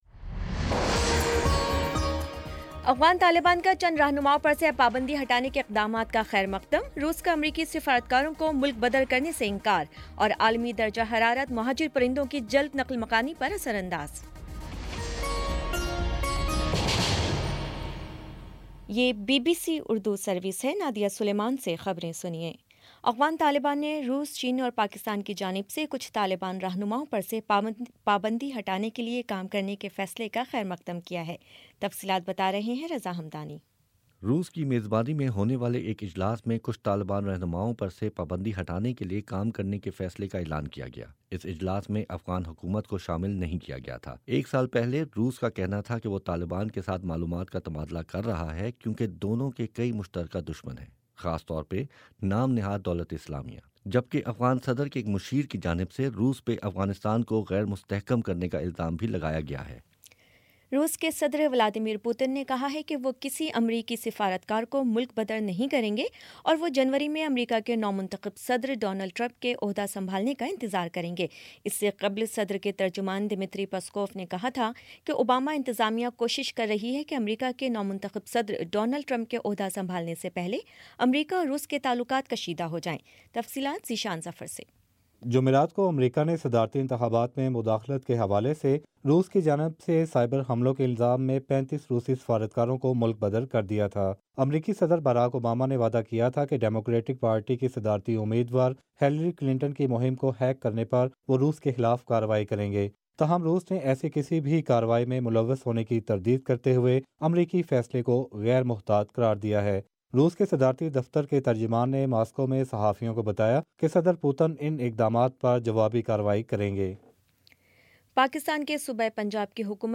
دسمبر 30 : شام سات بجے کا نیوز بُلیٹن